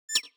Click Back (3).wav